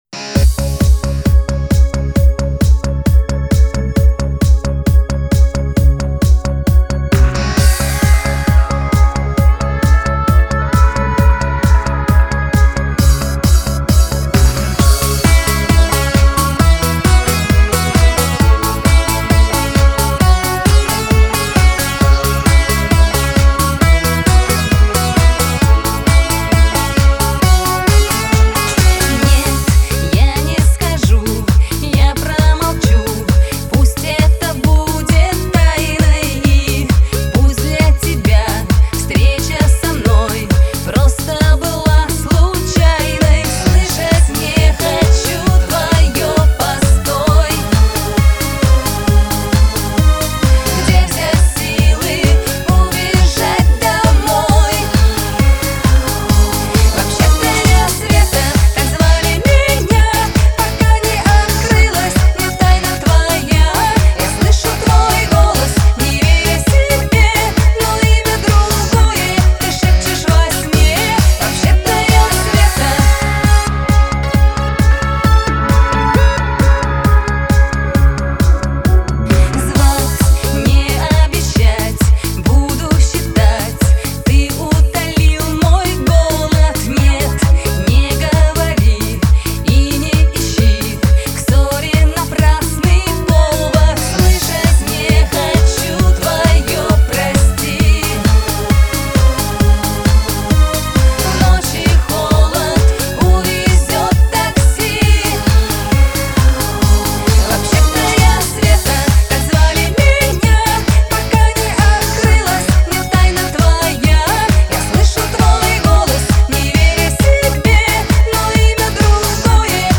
Жанр: Эстрада, Попса